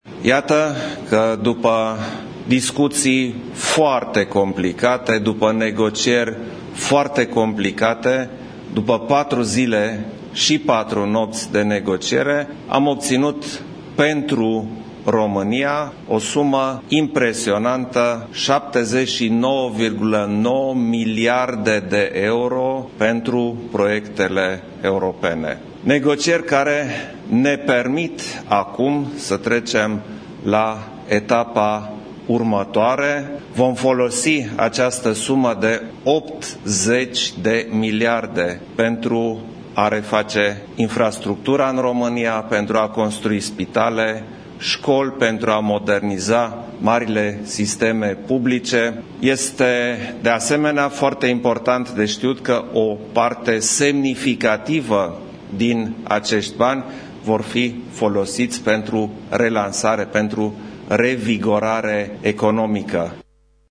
Banii vor fi utilizați pentru construcţia de spitale, şcoli, modernizarea marilor sisteme publice, precum şi reconstrucţia economică, a declarat în această dimineață, președintele Klaus Iohannis:
21-iul-iohannis.mp3